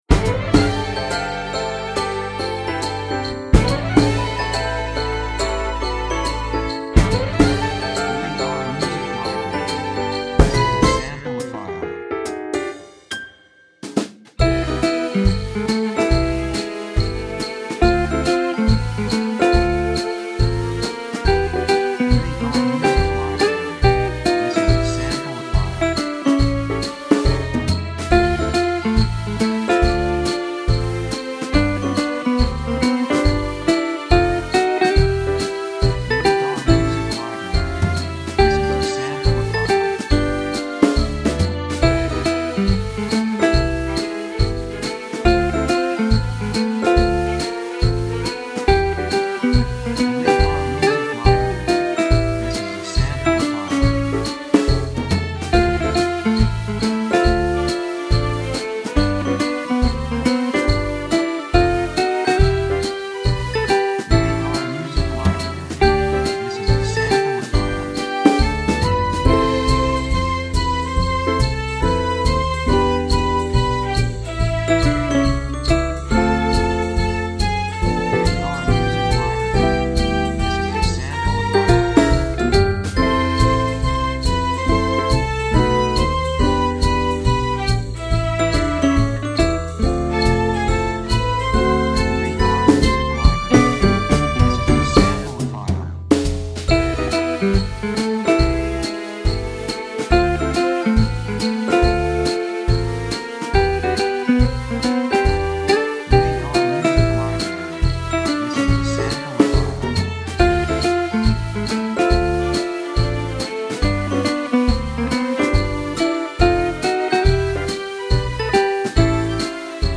雰囲気幸せ, 高揚感, 喜び, 感情的
曲調ポジティブ
楽器ベル, ハープ, オーケストラ
サブジャンルクリスマス, オーケストラ
テンポやや速い
3:10 140 季節物, ジャズ